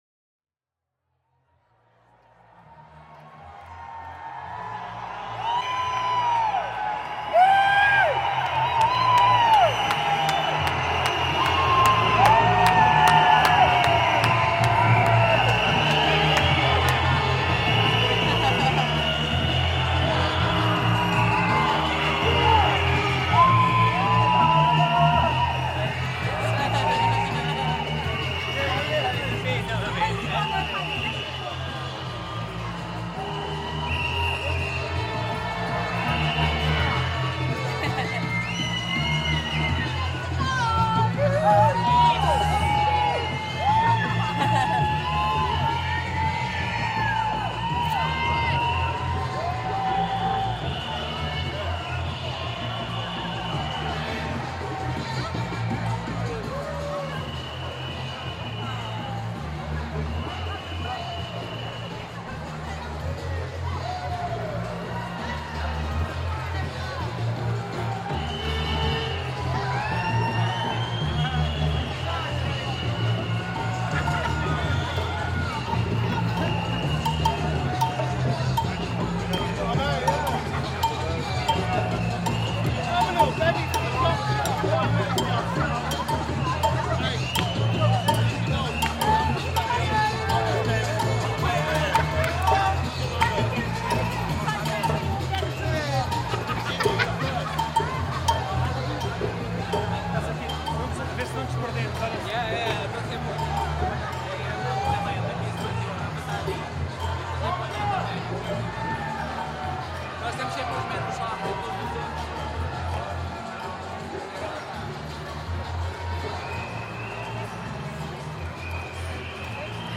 Several thousand anti-lockdown demonstrators marched through central London on a Saturday in April 2021 despite restrictions on mass gatherings during the COVID-19 pandemic.
Part of the StayHomeSounds project to map and reimagine the sounds of the Covid-19 lockdowns.